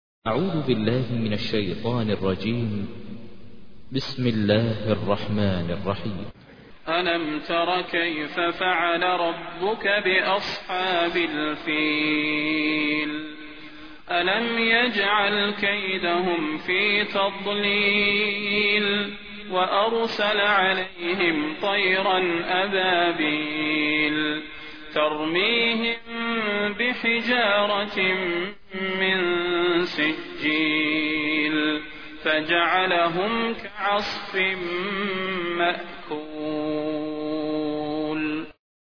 تحميل : 105. سورة الفيل / القارئ ماهر المعيقلي / القرآن الكريم / موقع يا حسين